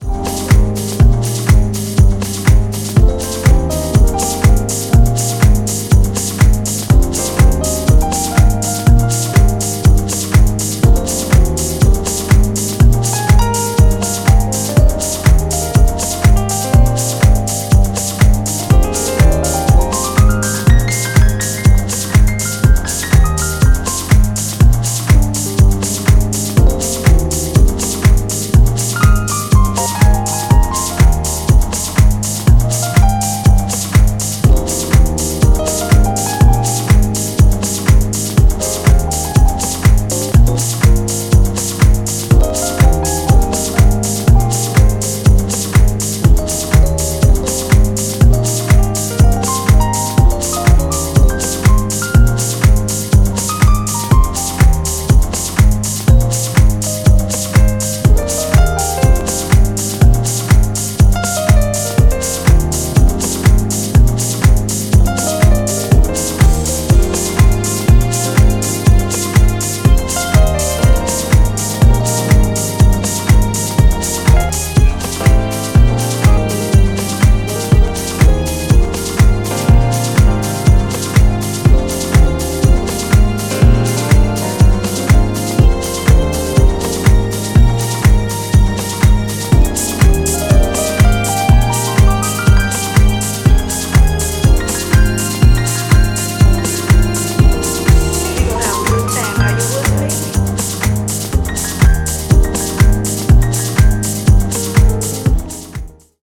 the dark, filtered chugger
an amazing piano solo